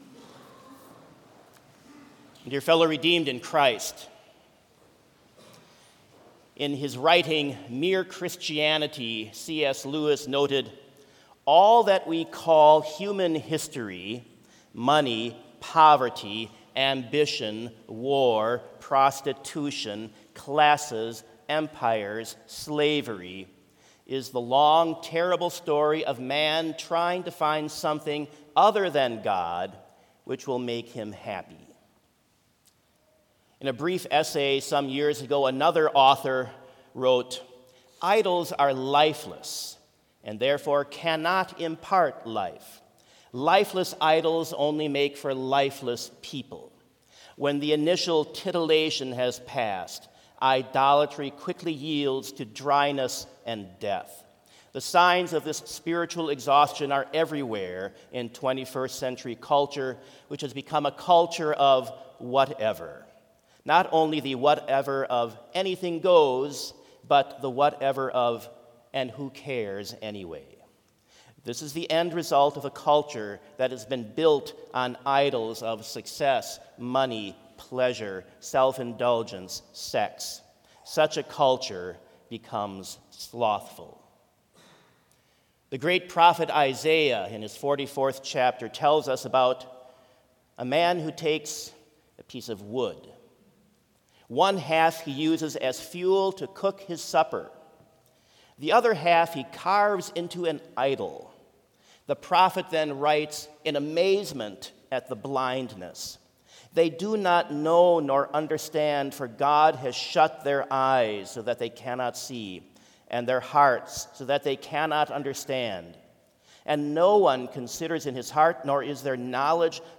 Complete Service
(spoken responsively P: odd # verses / C: even # verses & Glory be to the Father...)
This Chapel Service was held in Trinity Chapel at Bethany Lutheran College on Wednesday, October 11, 2023, at 10 a.m. Page and hymn numbers are from the Evangelical Lutheran Hymnary.